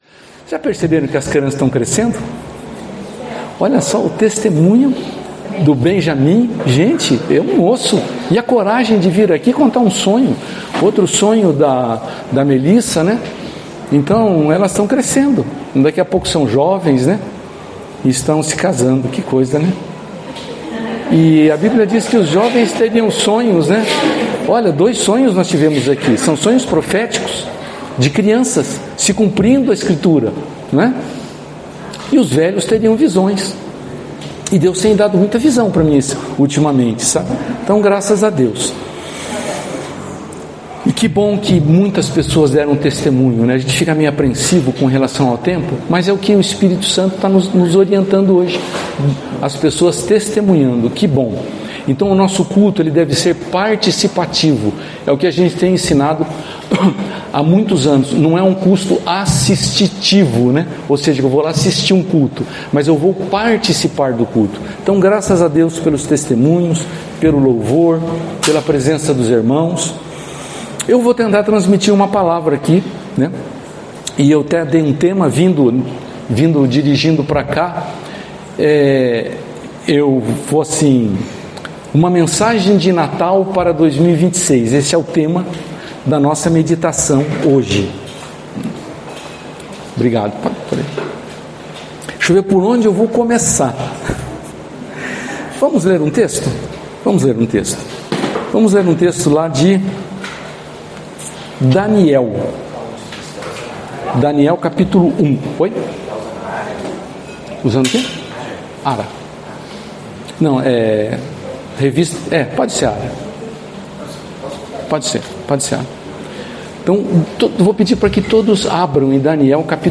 no culto do dia 21/12/2025 – Tema: Uma mensagem de natal para 2026
Palavras ministradas